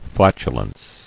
[flat·u·lence]